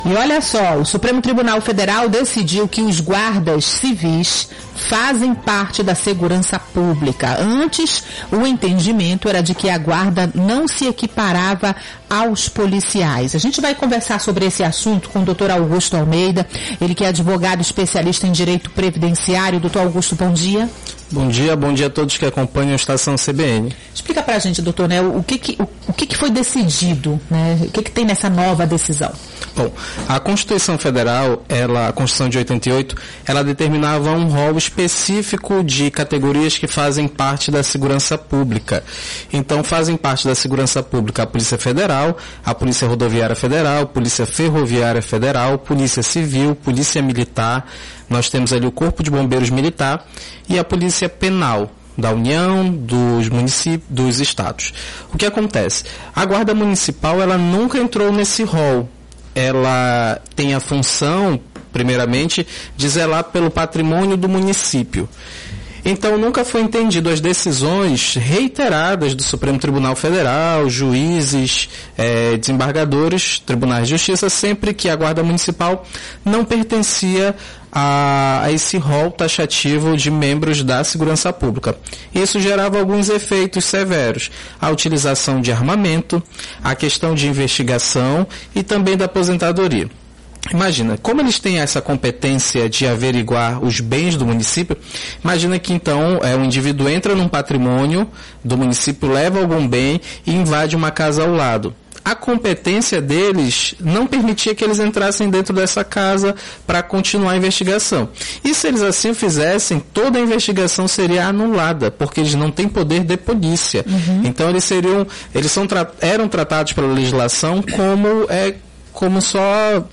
Em entrevista ao Estação CBN desta terça-feira (29)